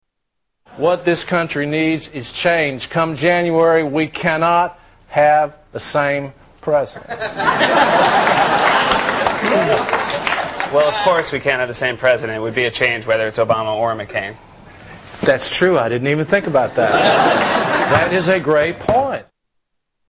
Tags: Comedians Darrell Hammond Darrell Hammond Impressions SNL Television